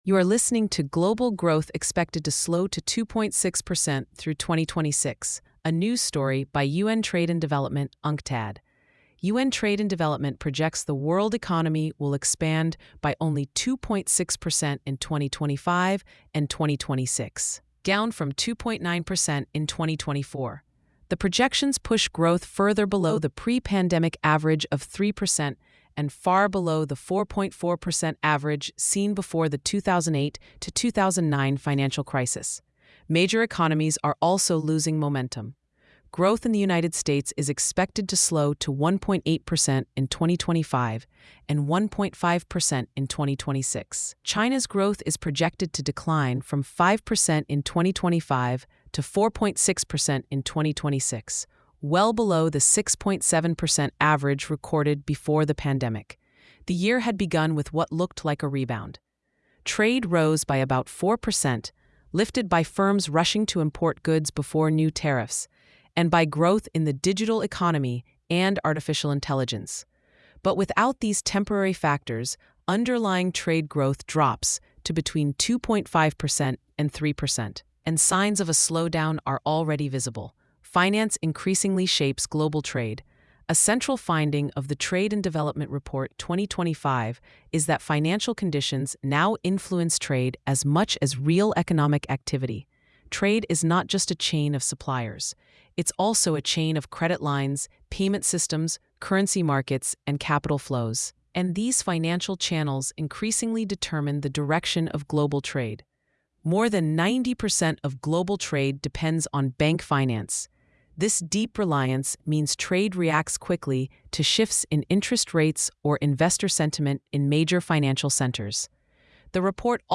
Narrated by AI. Inconsistencies may occur.